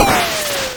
IcicleFall.wav